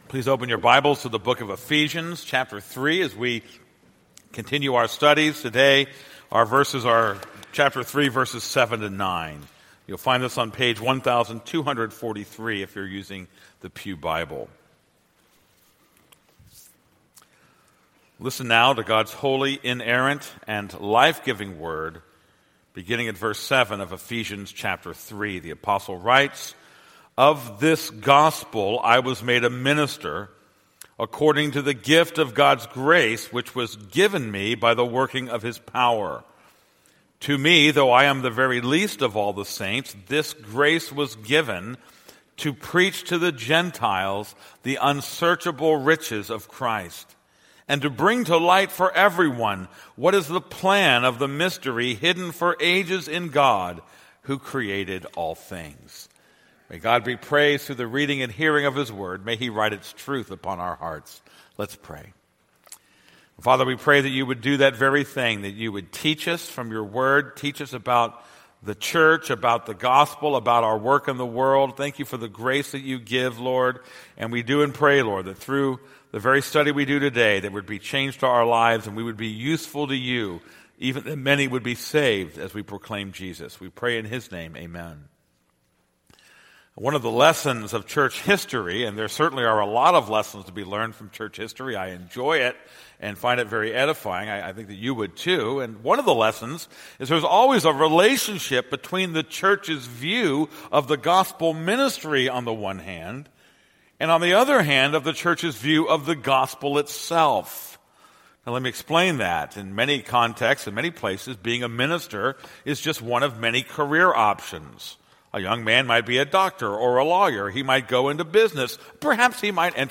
This is a sermon on Ephesians 3:7-9.